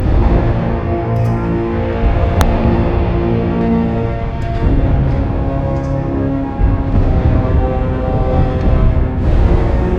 可以感受下用该功能生成的48kHz高保真蟋蟀声、“世界毁灭”氛围感音效：
提示词：形容世界毁灭的音乐